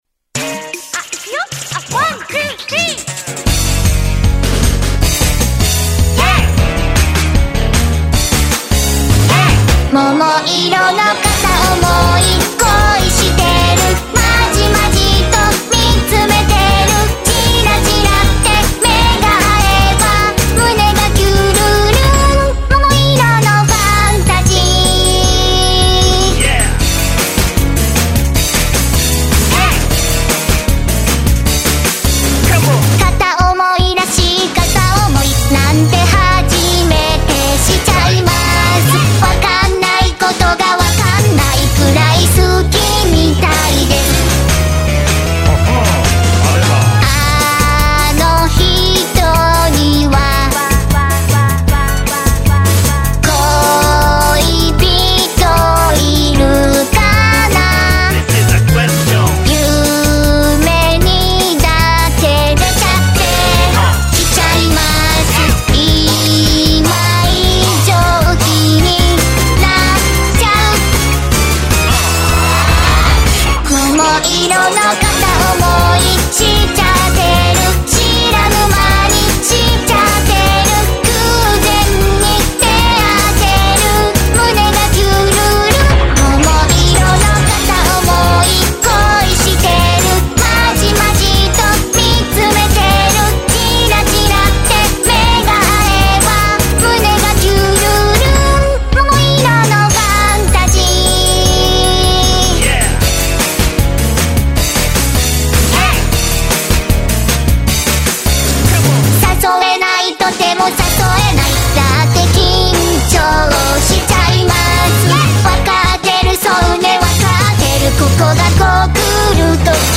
発音が聞き取りやすい声でお気に入りです。